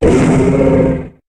Cri de Polagriffe dans Pokémon HOME.